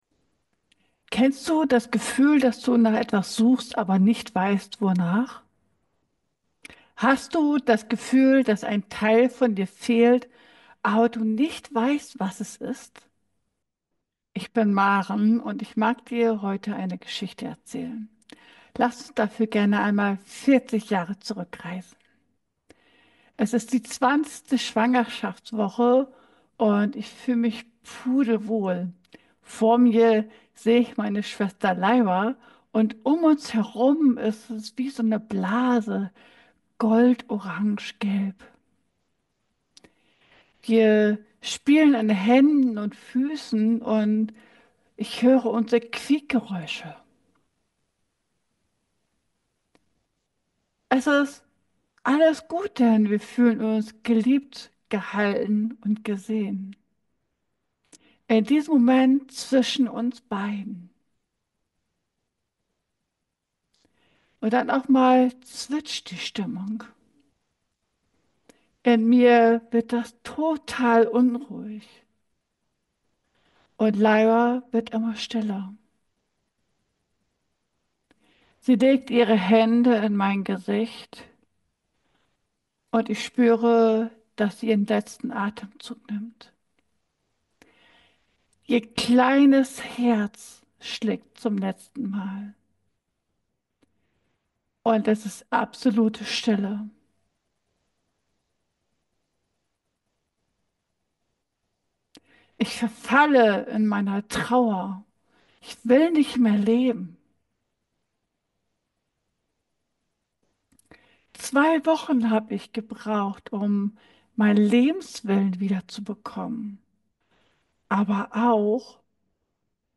Beschreibung vor 9 Monaten In diesem tiefgehenden und berührenden Gespräch teile ich meine persönliche Reise durch Trauer, Heilung und Selbstfindung.